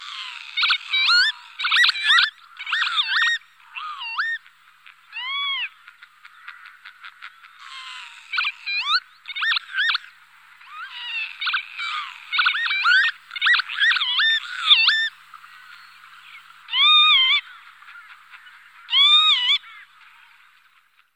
Звуки чибиса
На этой странице собраны звуки чибиса — звонкие и мелодичные крики этой птицы.